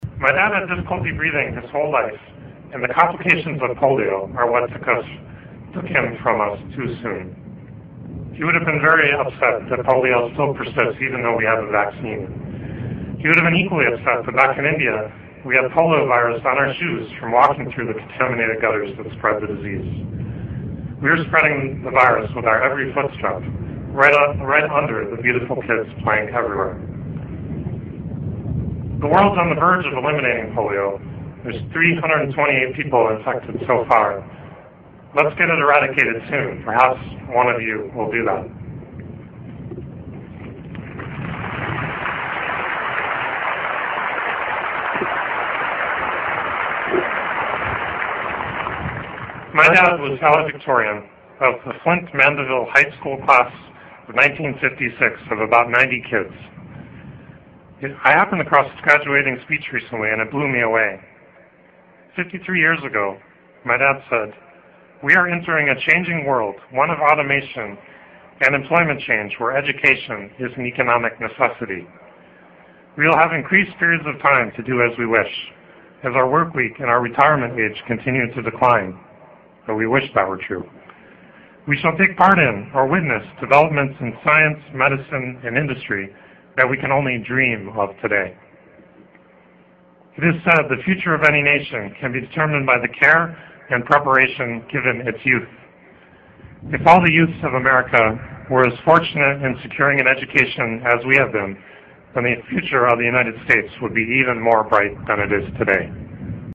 名人励志英语演讲 第107期:当梦想来临时抓住它(8) 听力文件下载—在线英语听力室